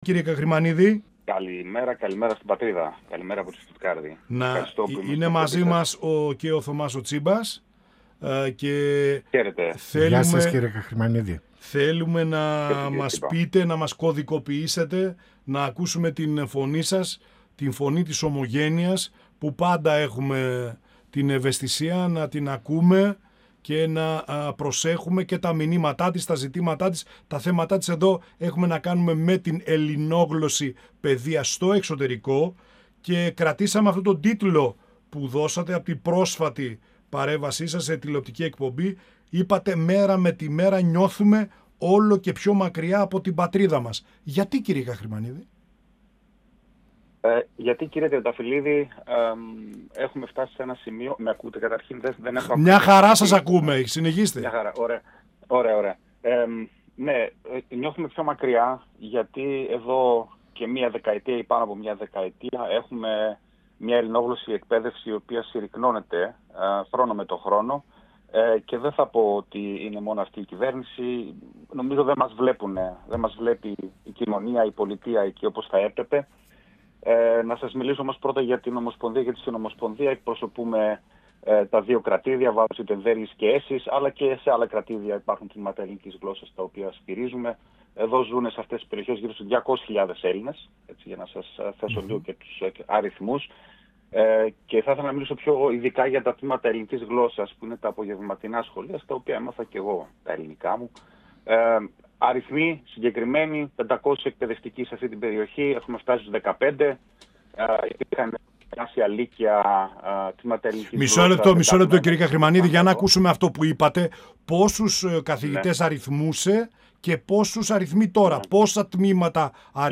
102FM Πανοραμα Επικαιροτητας Συνεντεύξεις